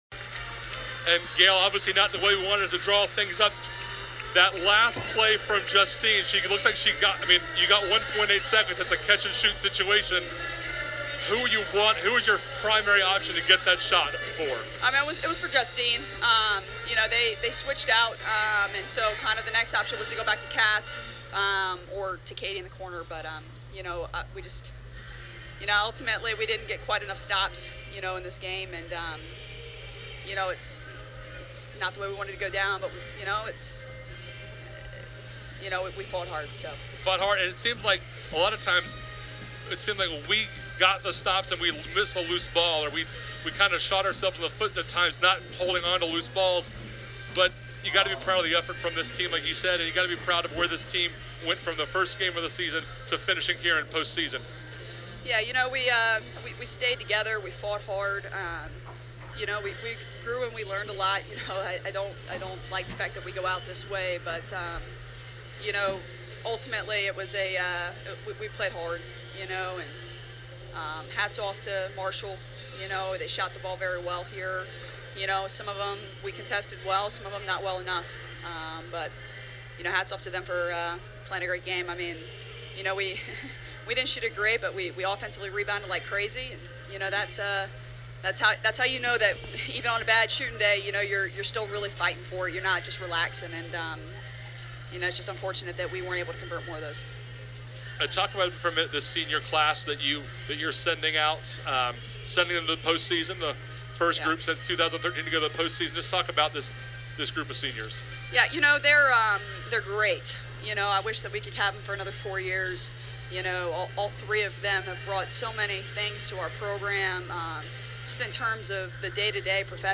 Postgame Interview
Post Game Marshall.mp3